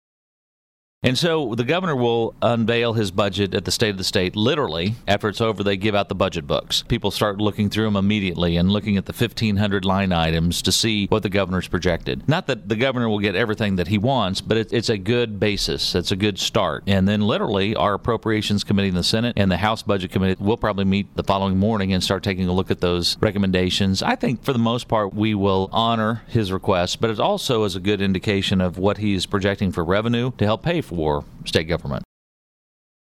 The following audio comes from the above interview with Sen. Pearce, for the week of Jan. 12, 2015.